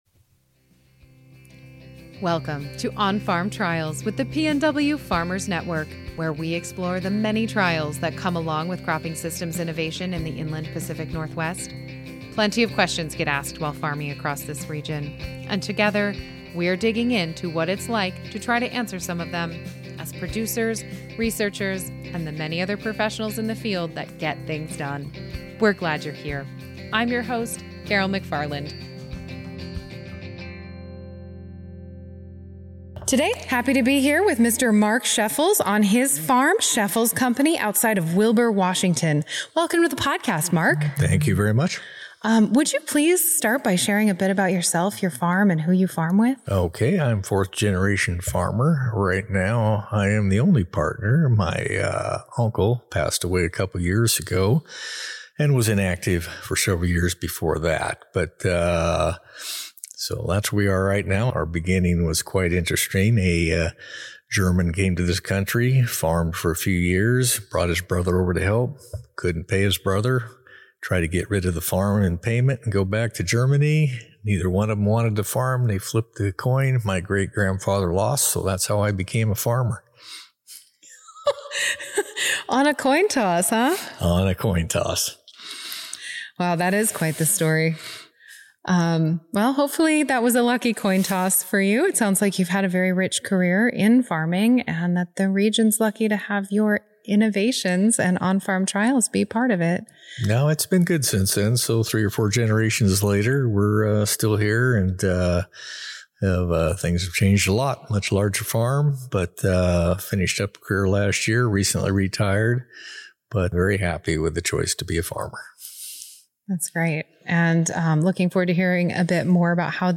Join this conversation about On-Farm Trials with recently-retired farmer